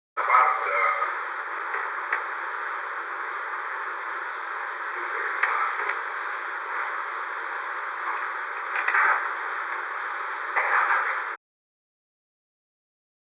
Secret White House Tapes
Conversation No. 448-13
Location: Executive Office Building
The President met with an unknown person